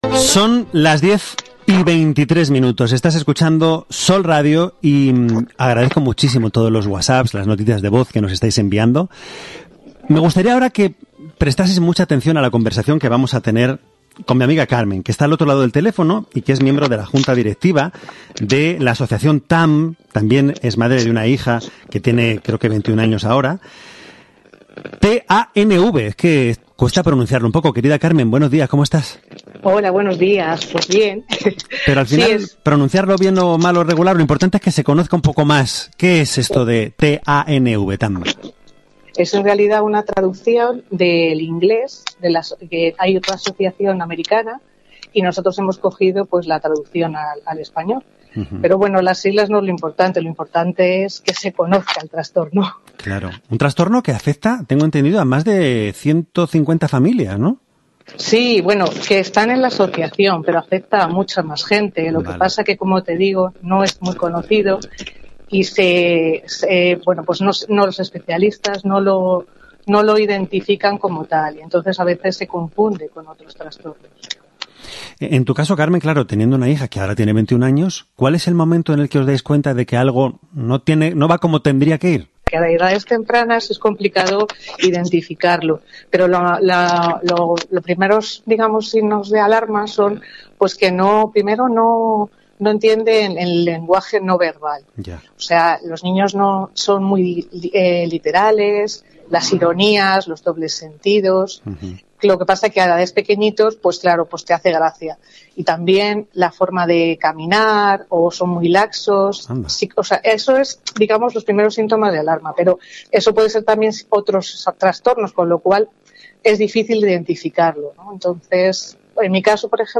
ENTREVISTA EN SOL RADIO MADRID - TANV
audio-entrevista-en-sol-radio-madrid.mp3